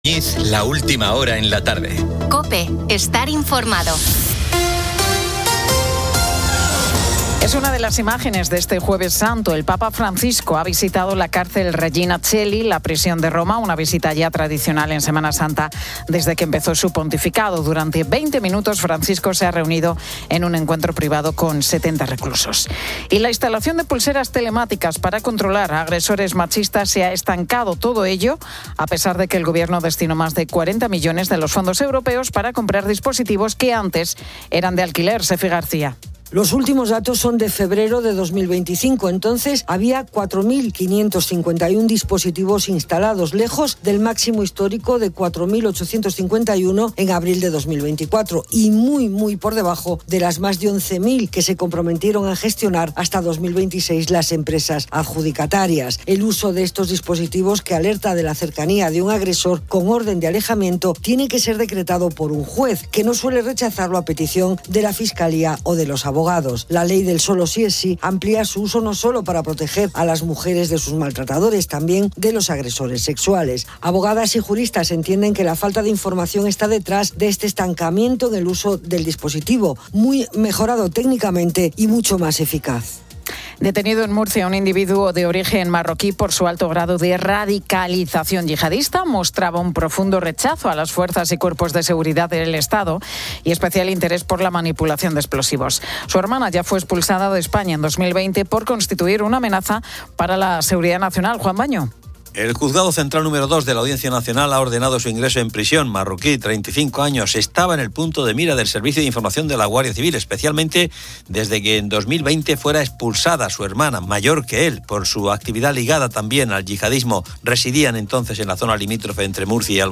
Y terminamos con música de Semana Santa, con la banda Cruz del Humilladero de Málaga y el coro de la Penitente Hermandad de Jesús Yacente de Zamora.